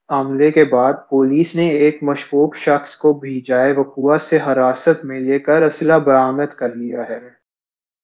deepfake_detection_dataset_urdu / Spoofed_TTS /Speaker_13 /17.wav